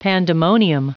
Prononciation du mot pandemonium en anglais (fichier audio)
Prononciation du mot : pandemonium